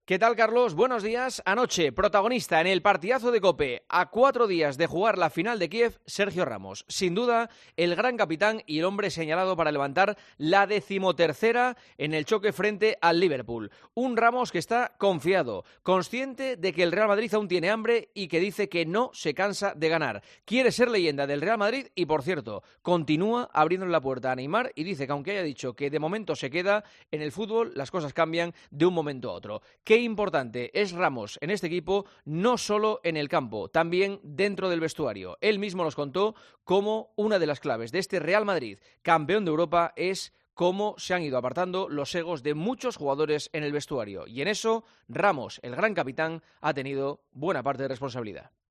El comentario de Juanma Castaño
Escucha el análisis del director de 'El Partidazo' de COPE en 'Herrera en COPE'